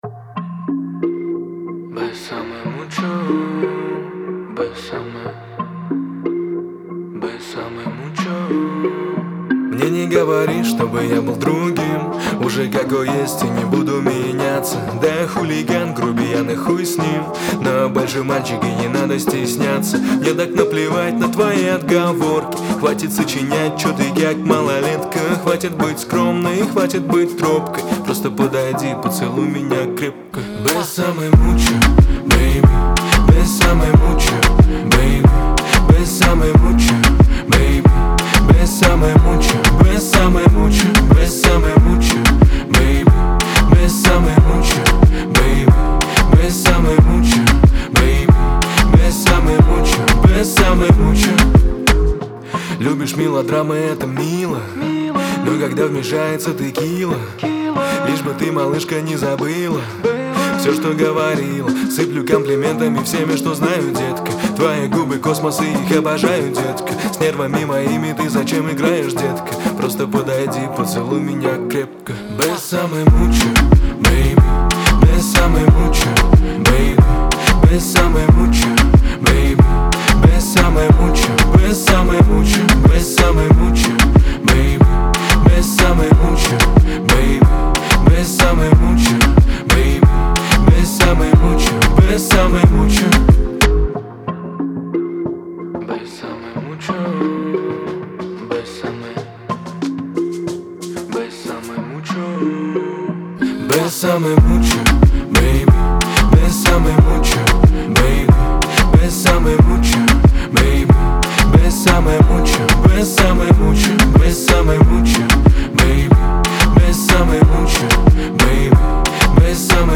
это классическая латиноамериканская баллада